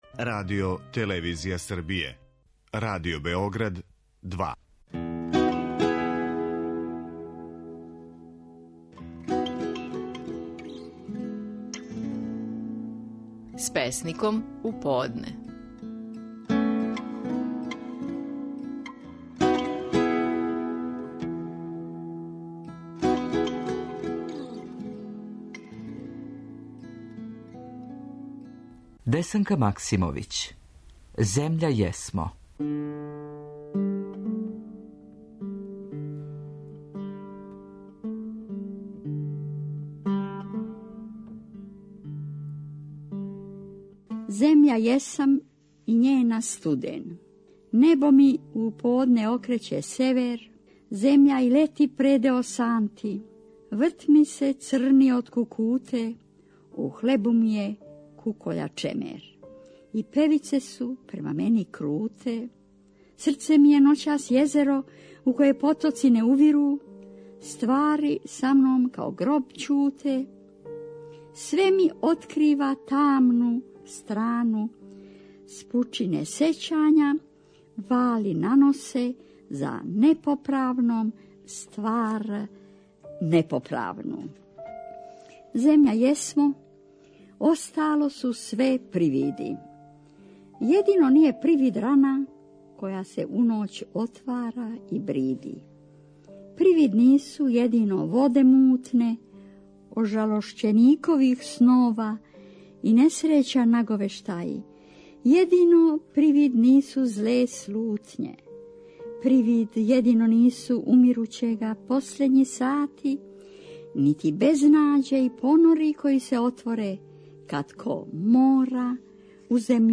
Стихови наших најпознатијих песника, у интерпретацији аутора.
У данашњој емисији слушамо како је стихове своје песме „Земља јесмо" говорила Десанка Максимовић.